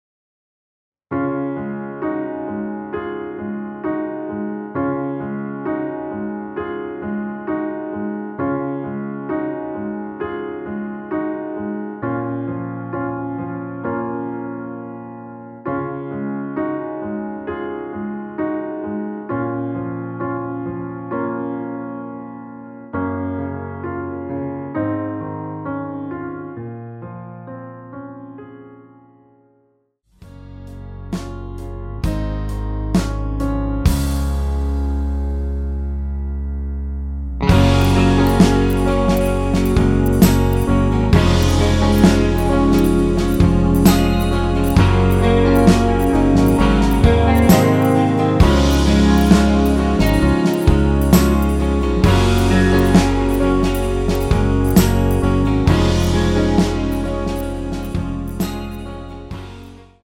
원키에서(-1)내린 MR입니다.
Db
◈ 곡명 옆 (-1)은 반음 내림, (+1)은 반음 올림 입니다.
앞부분30초, 뒷부분30초씩 편집해서 올려 드리고 있습니다.
중간에 음이 끈어지고 다시 나오는 이유는